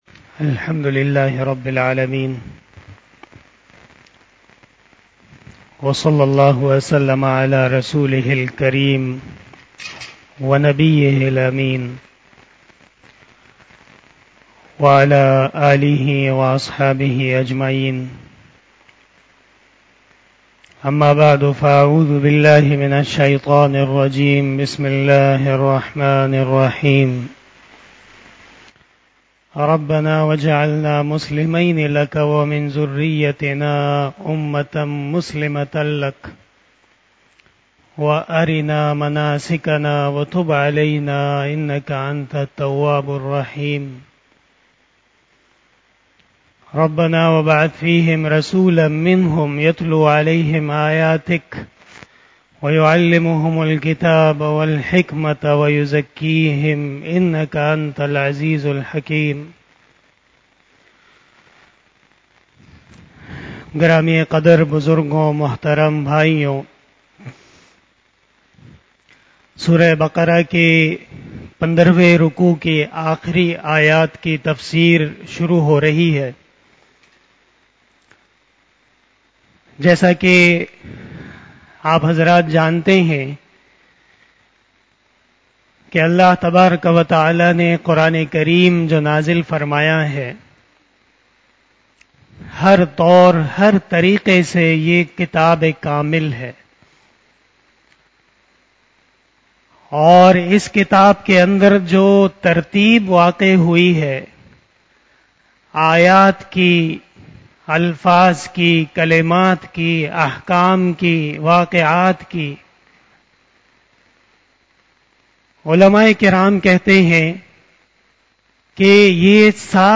بیان شب جمعه المبارک